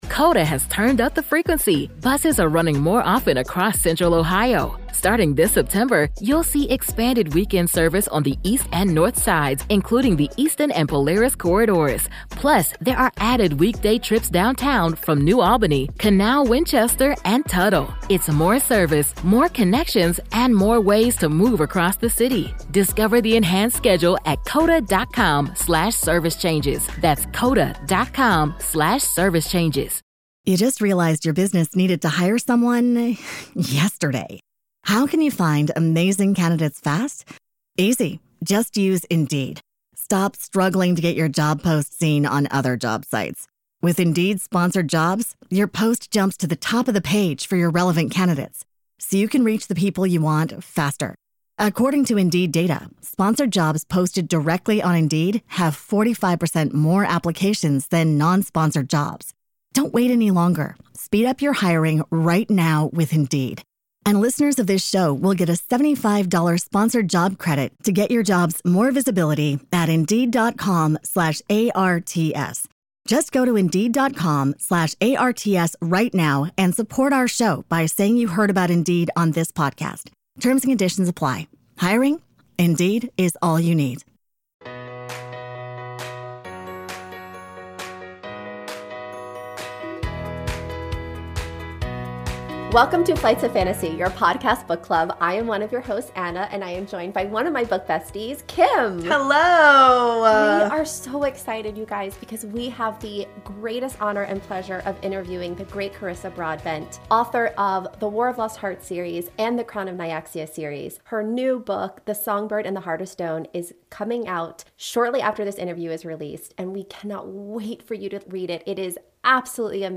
S4 : Ep 26 - Author Interview : Carissa Broadbent - Crowns of Nyaxia Series